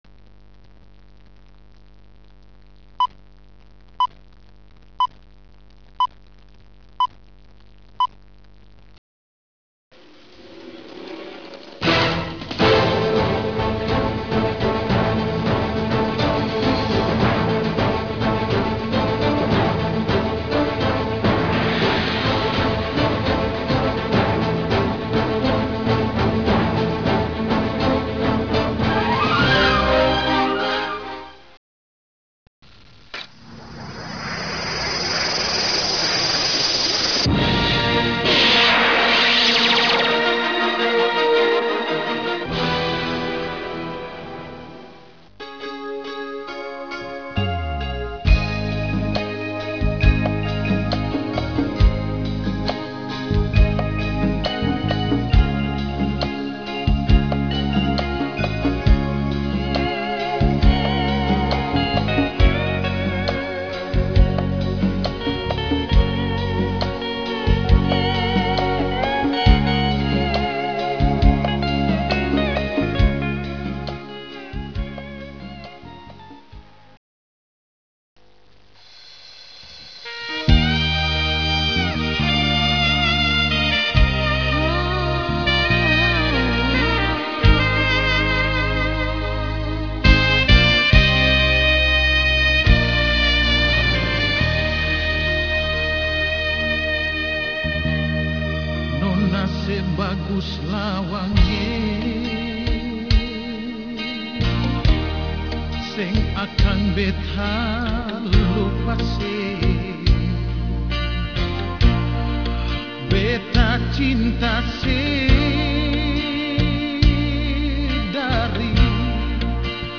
lagu pop daerah Ambon